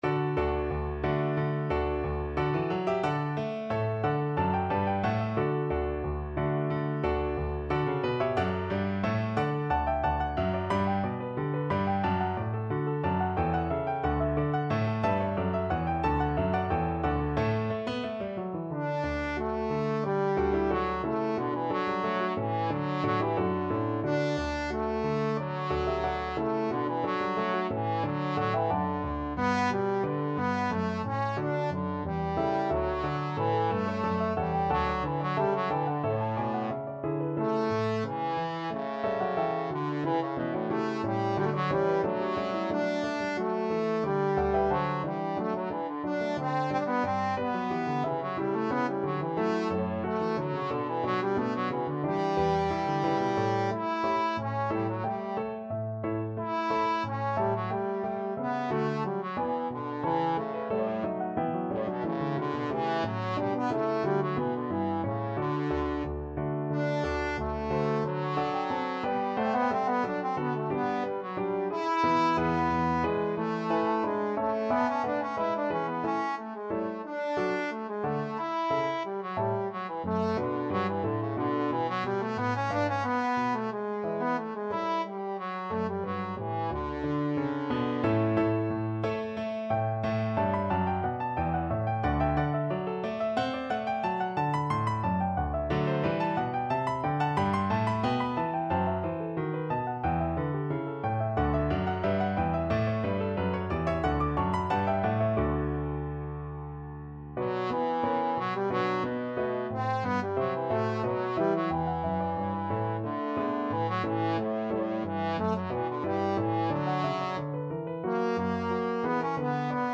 Classical
Trombone
Time Signature: 2/4 ( View more 2/4 Music ) Tempo Marking: ~ = 90 Score Key: D major (Sounding Pitch) ( View more D major Music for Trombone ) Range: A3-E